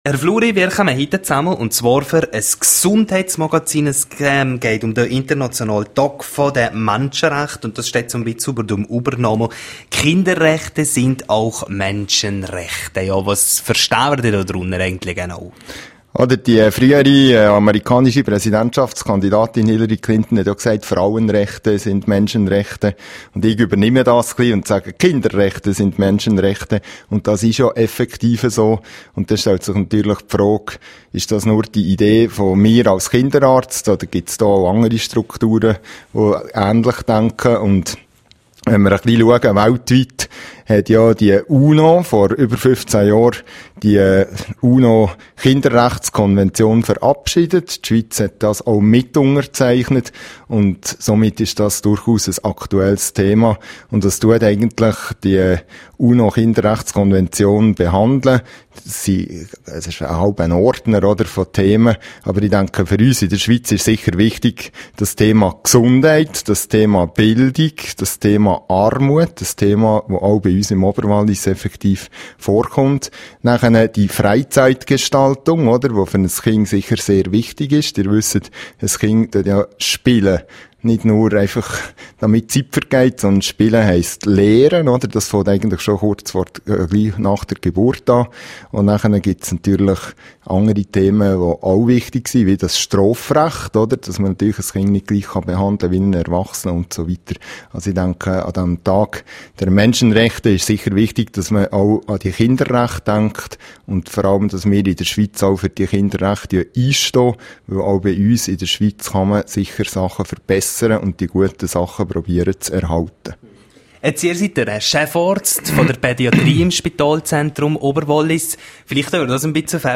Weitere Ausführungen zum Thema gibt es vom Kinderarzt im detaillierten Interview./bj